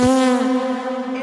High Pitch Fart With Reverb - Botón de Efecto Sonoro